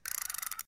egg-timer.ogg